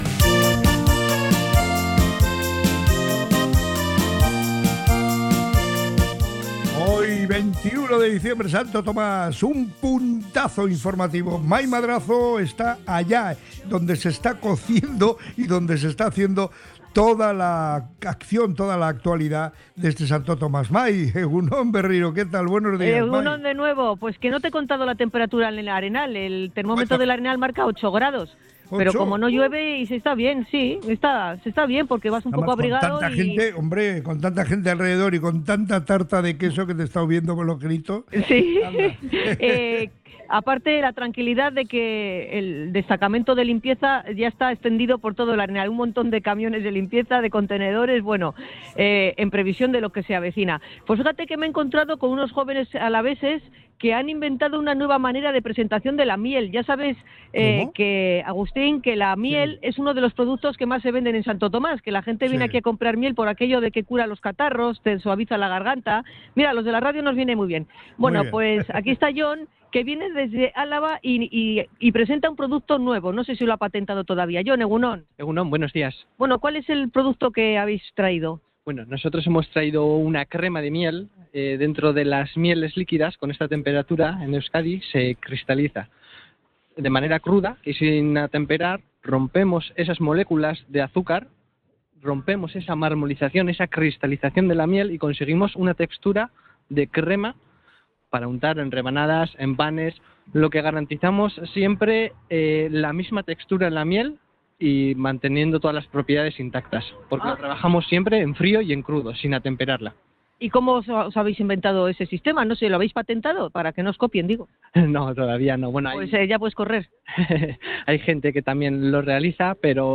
ha visitado los puestos de Santo Tomás en el Arenal de Bilbao